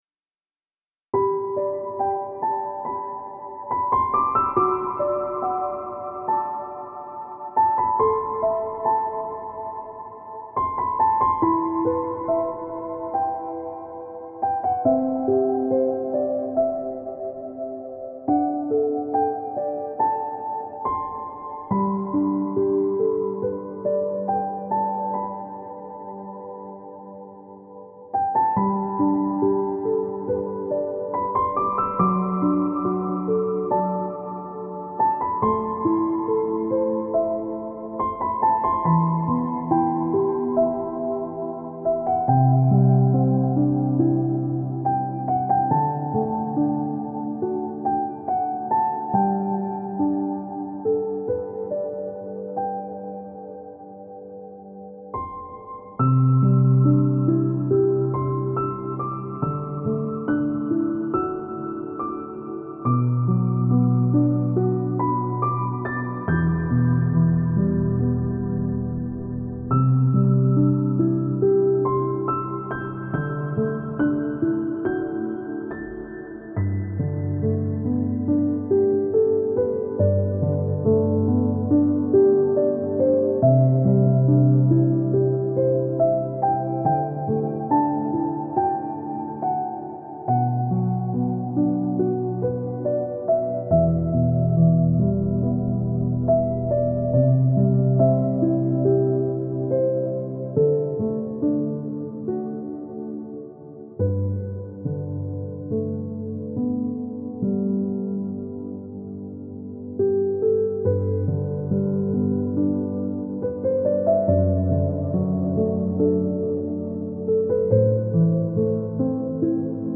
инструментальной поп-музыки